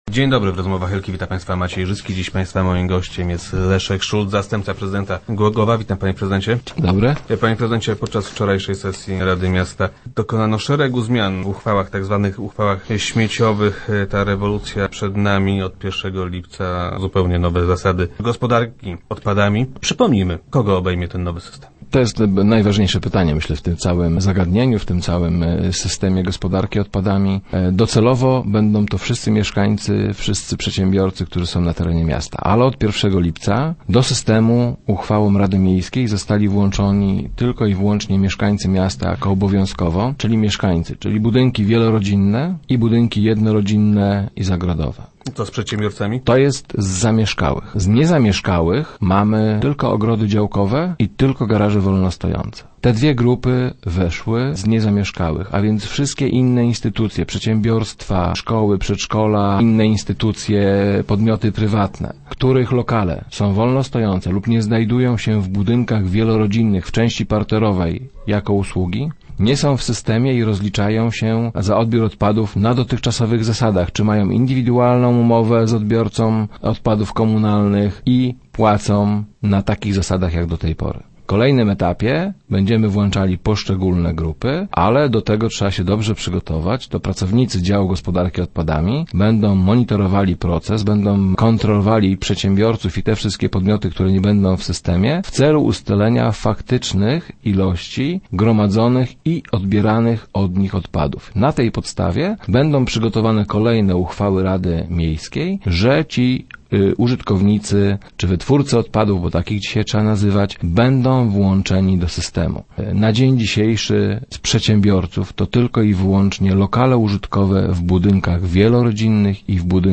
Jak twierdzi Leszek Szulc, zastępca prezydenta Głogowa, wynikają one z sygnałów płynących od mieszkańców miasta. O zmianach wiceprezydent opowiadał w środowych Rozmowach Elki.